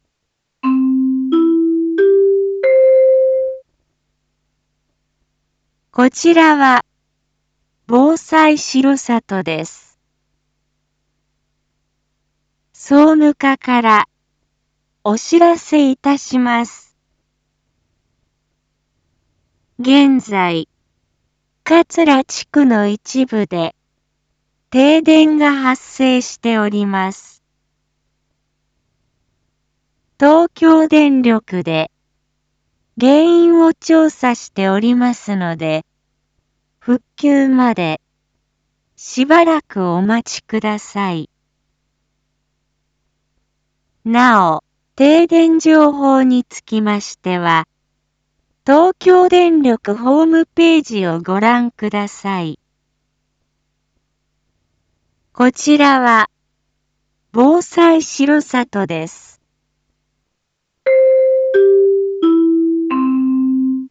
一般放送情報
Back Home 一般放送情報 音声放送 再生 一般放送情報 登録日時：2021-10-22 17:15:05 タイトル：R3.10.22 桂地区停電(地区限定) インフォメーション：こちらは防災しろさとです。